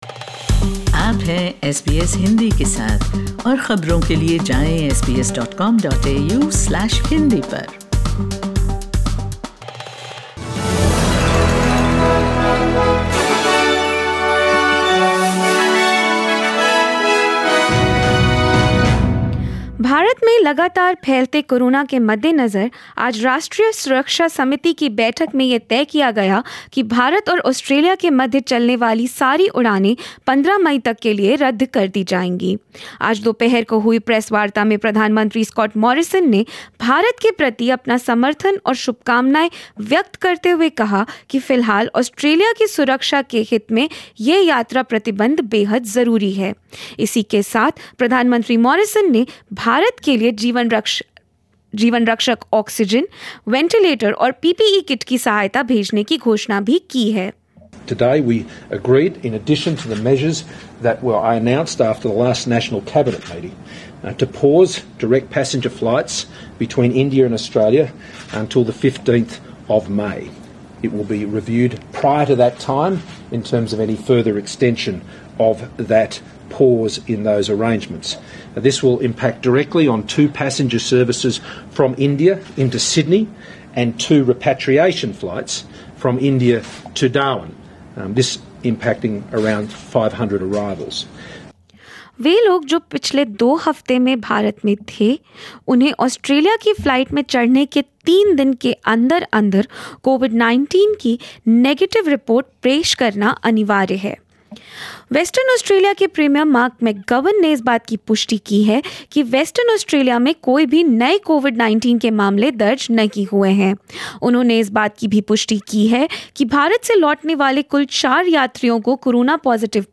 Listen to the latest news of India and Australia in Hindi. 27/04/21